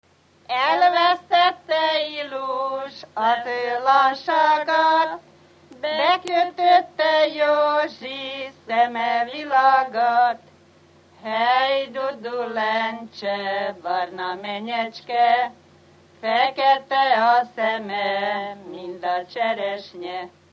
Felföld - Bars vm. - Aha
Műfaj: Lakodalmas
Stílus: 2. Ereszkedő dúr dallamok